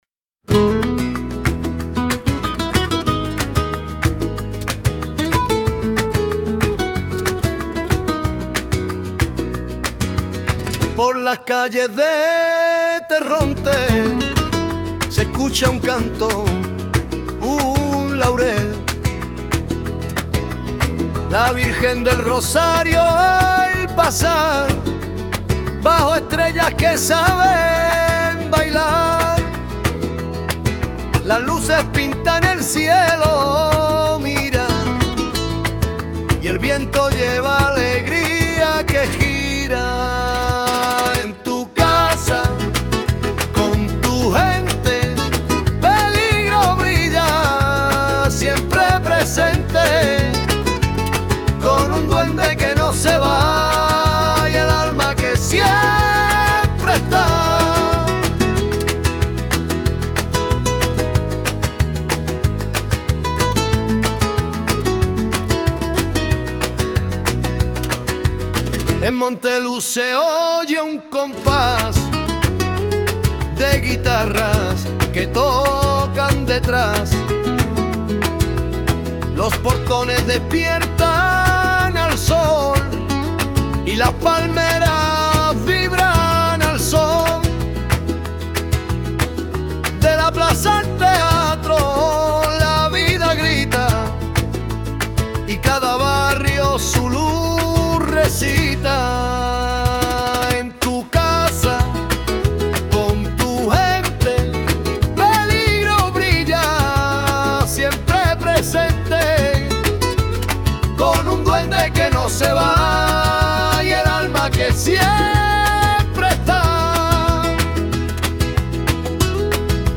Villancico-rumbero.mp3